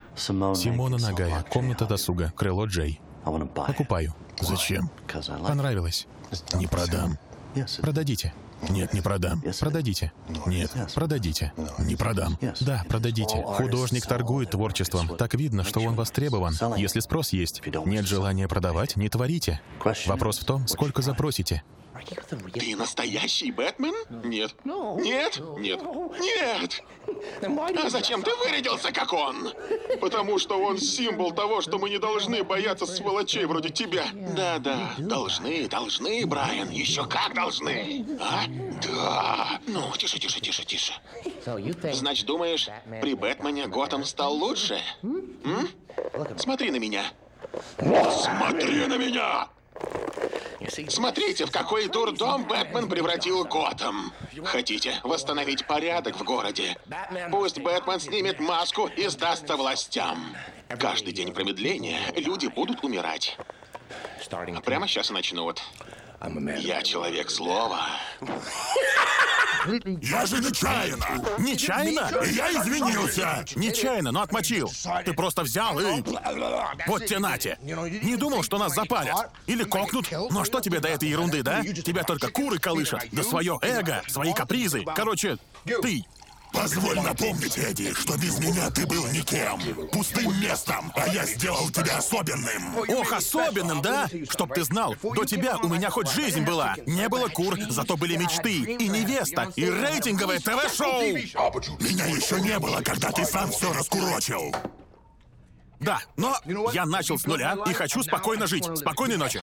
Пример звучания голоса
Муж, Закадровый текст/Средний
• Кабина + Focusrite 2i2 3rd gen + dbx 286s + Rode NTG3b • Качественные кабели, тихое помещение, ничего не шумит.